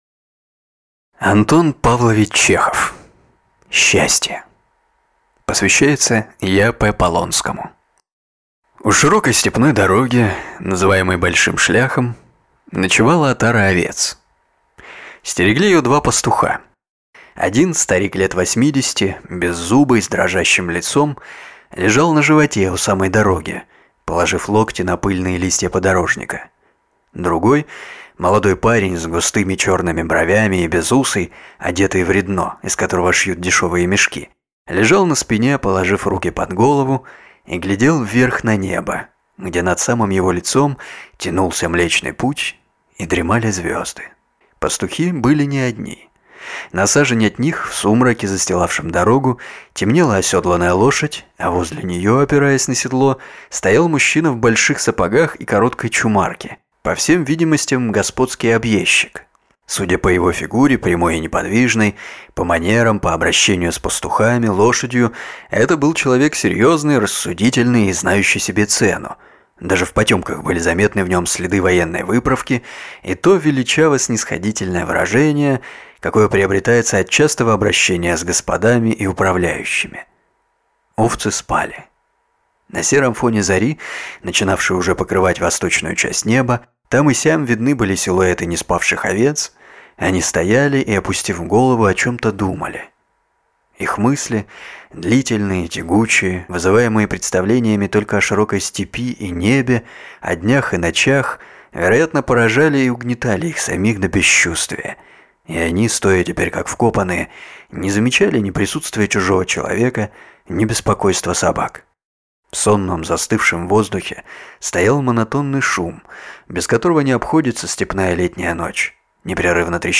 Аудиокнига Счастье | Библиотека аудиокниг